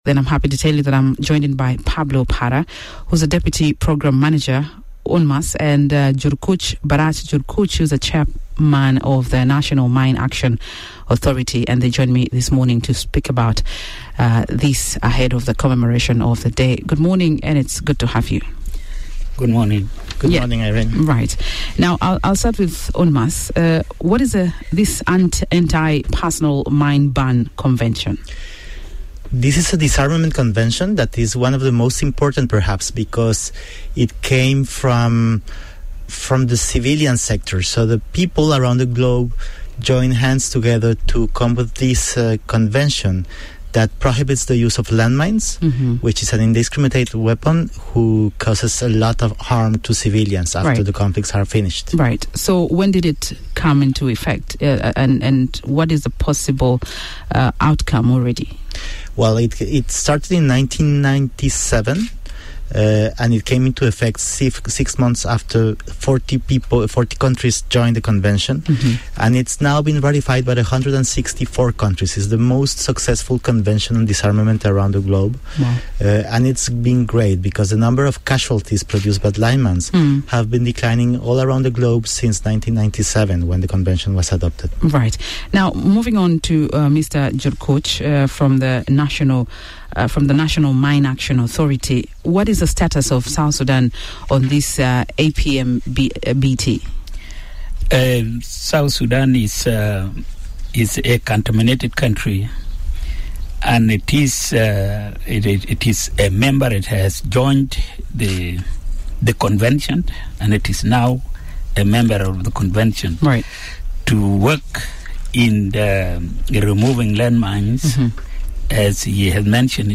Chairman of the National Mine Action Authority featured on Miraya Breakfast to talk about the day and South Sudan’s extension request to the APMBT Article Five Committee.